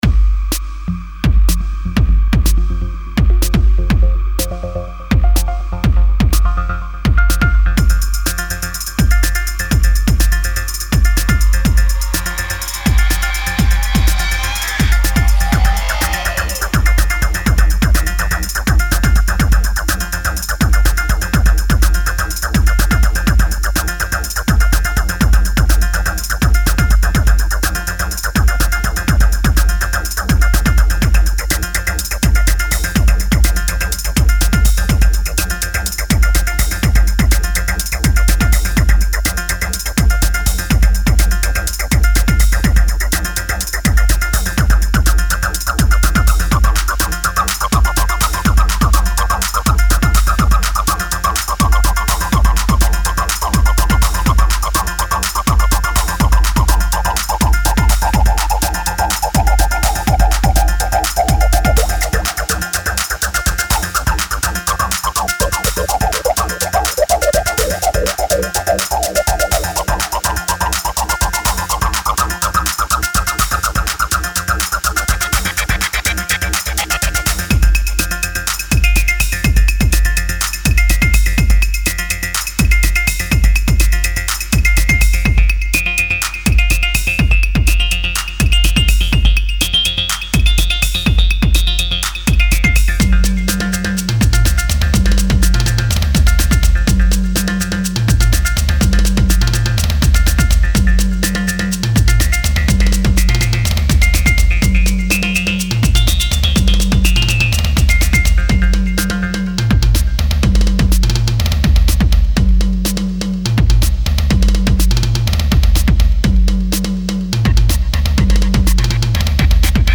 前2作はアシッド・ハウスのフォーカスした印象でしたが、今回はエレクトロに寄せています。